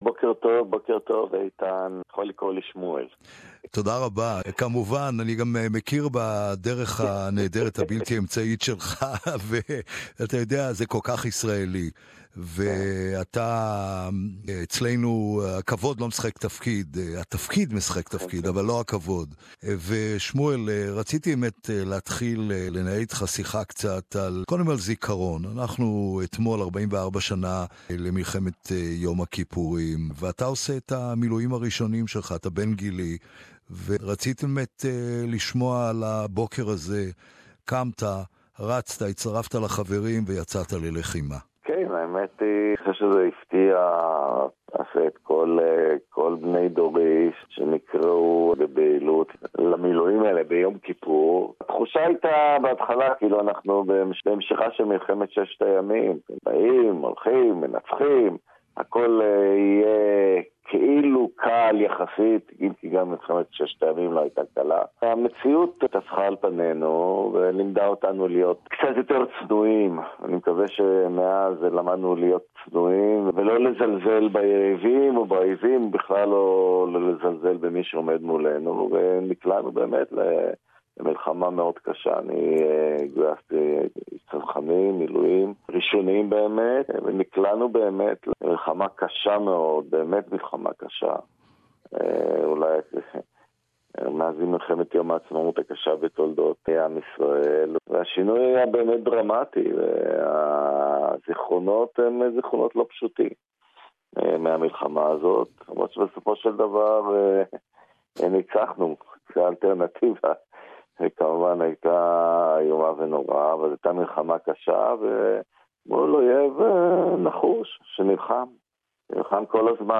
Interview with Israeli Ambassador to Australia Shmuel Ben-Shmuel on the 44th anniversary for Yom Kippur War and about his term in Australia Source: MFA